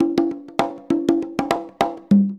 100 CONGAS05.wav